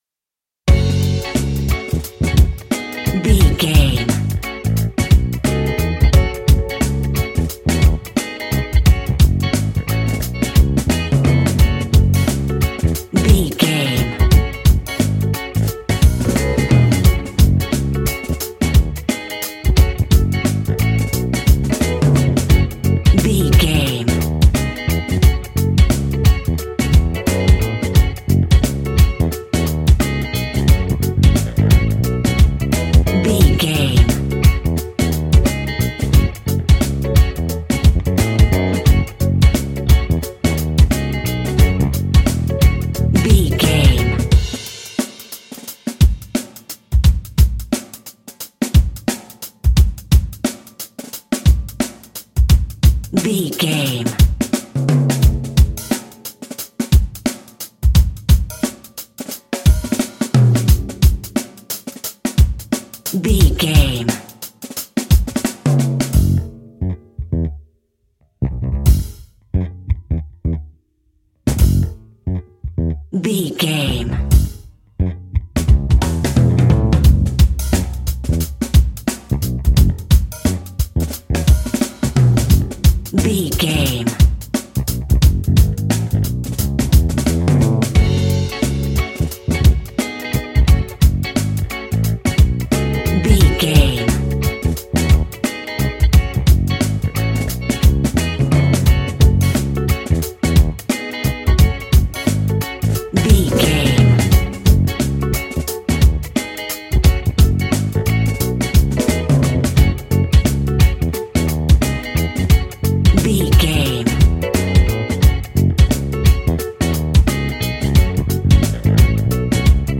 Aeolian/Minor
D♭
groovy
lively
electric guitar
electric organ
bass guitar
saxophone
percussion